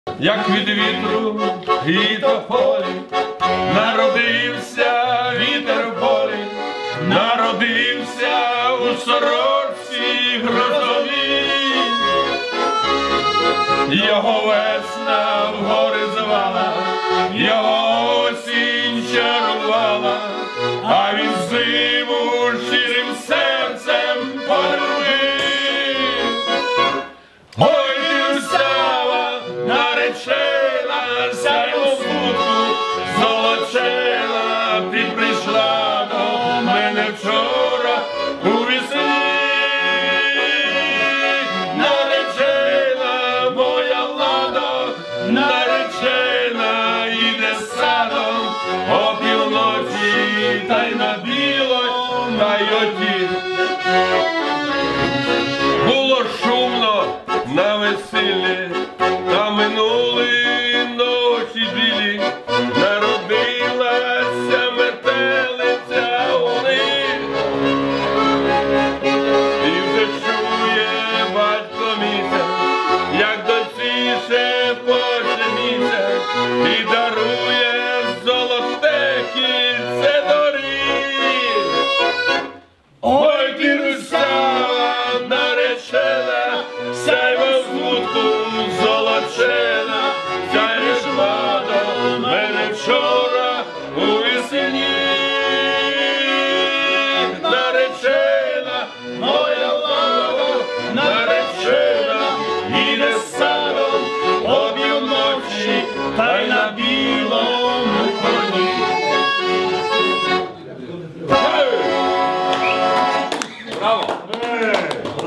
• Качество: 320 kbps, Stereo
Українська народна пісня